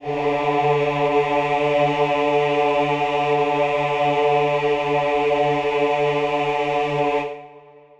Choir Piano
D3.wav